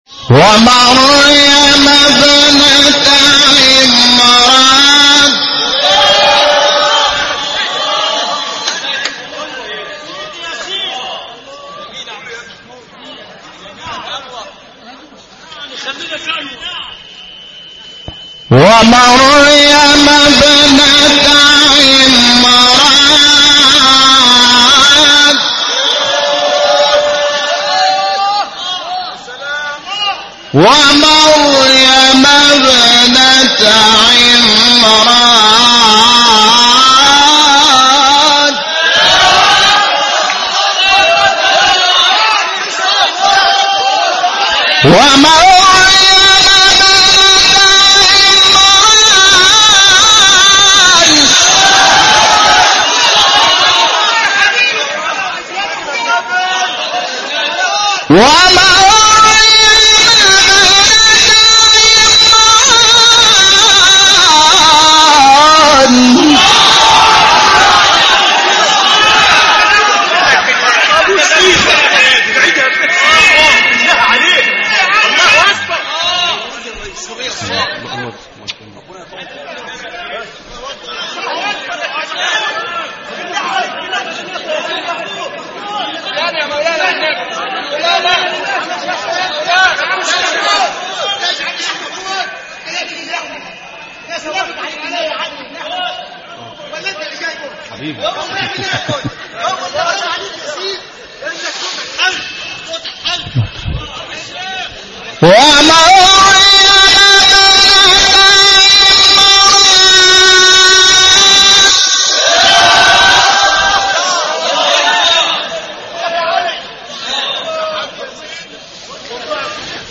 سوره : تحریم آیه : 21 استاد : محمود شحات مقام : ترکیب عجم صبا قبلی بعدی